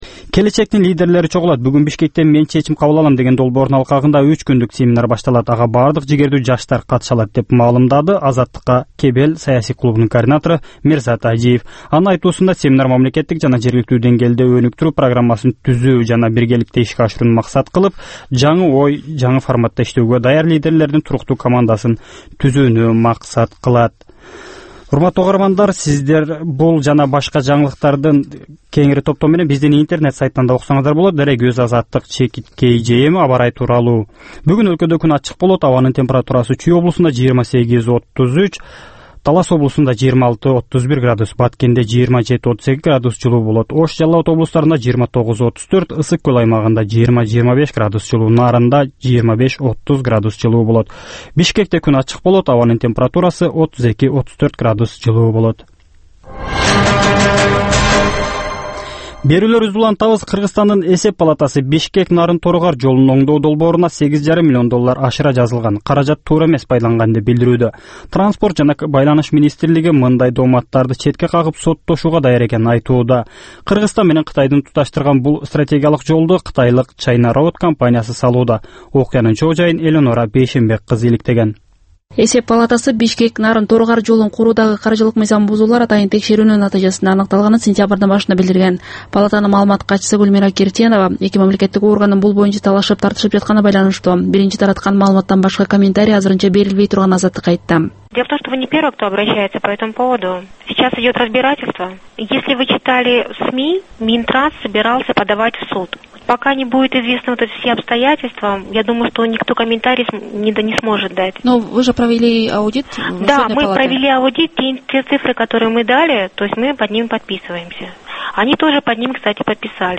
Бул таңкы үналгы берүү жергиликтүү жана эл аралык кабарлар, ар кыл орчун окуялар тууралуу репортаж, маек, талкуу, баян, күндөлүк басма сөзгө баяндама жана башка берүүлөрдөн турат. "Азаттык үналгысынын" бул таңкы берүүсү Бишкек убакыты боюнча саат 08:00ден 08:30га чейин обого чыгарылат.